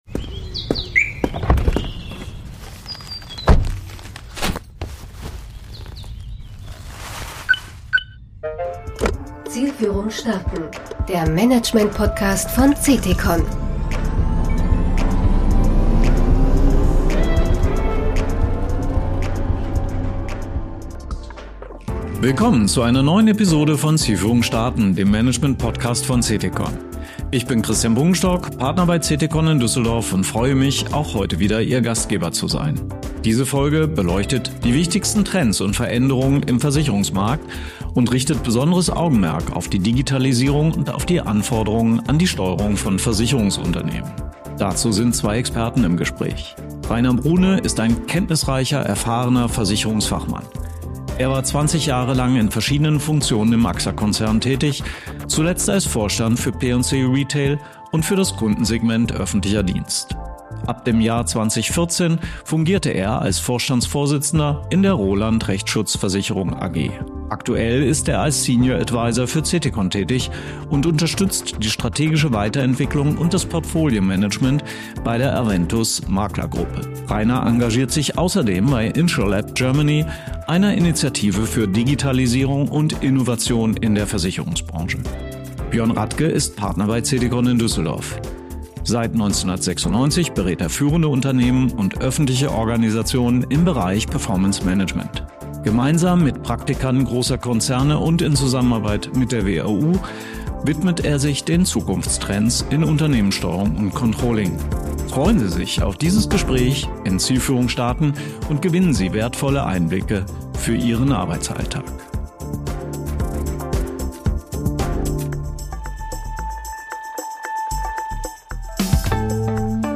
Diese Folge beleuchtet die wichtigsten Trends und Veränderungen im Versicherungsmarkt und richtet besonderes Augenmerk auf die Digitalisierung und auf die Anforderungen an die Steuerung von Versicherungsunternehmen. Dazu sind zwei Experten im Gespräch: